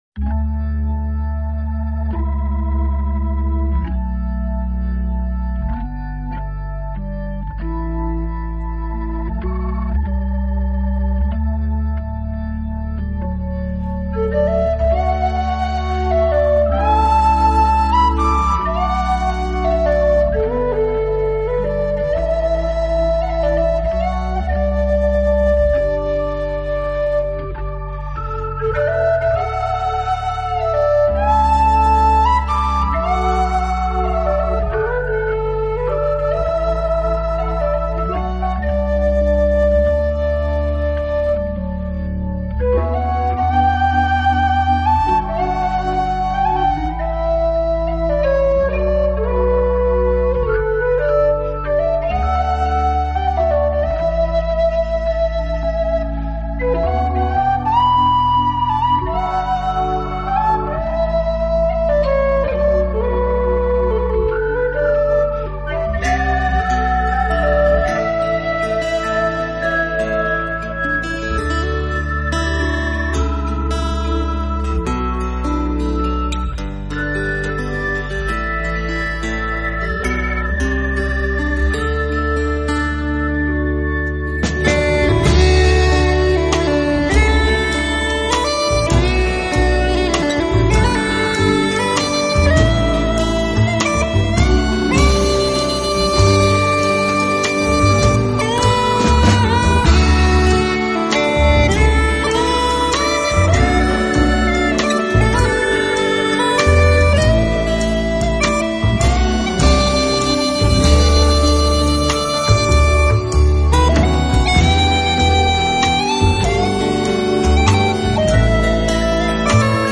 淡淡哀愁的器乐合奏辑
吉他、口琴、萨克斯、苏格兰鼓等则由他们的一干友人倾情献艺。